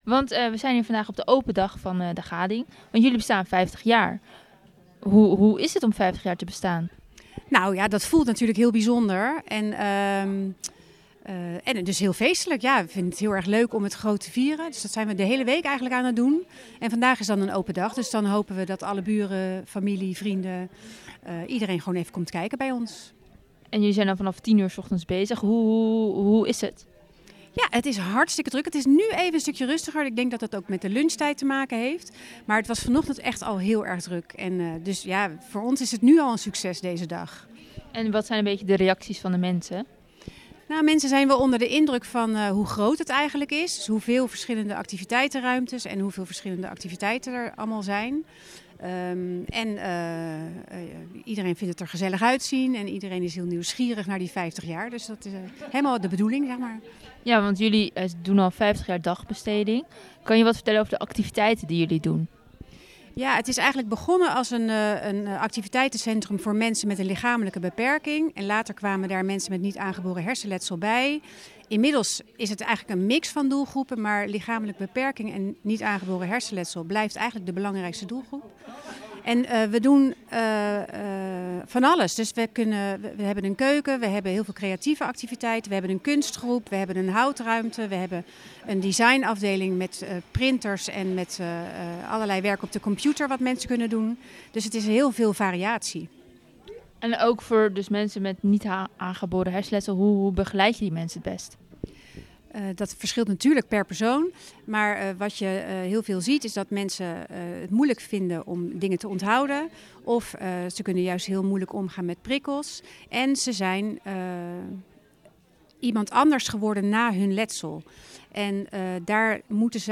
De feestweek in de dagcentrum Gading.